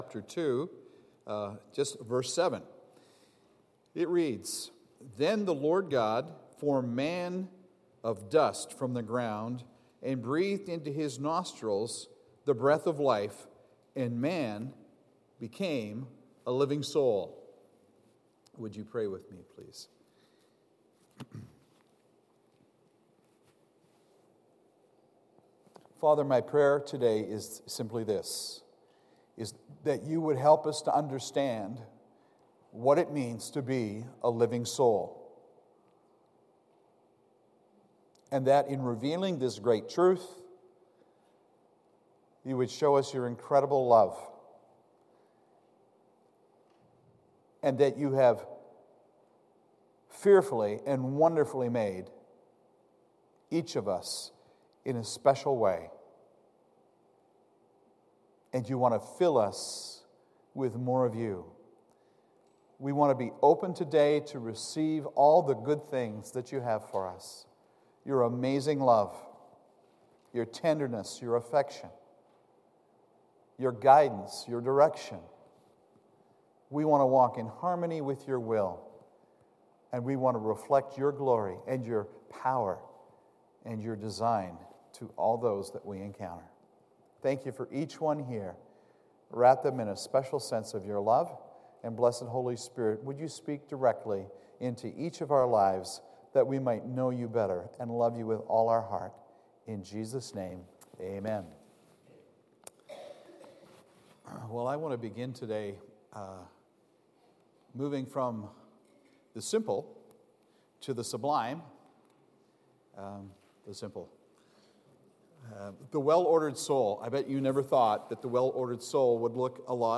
Sermons | Peninsula Mission Community Church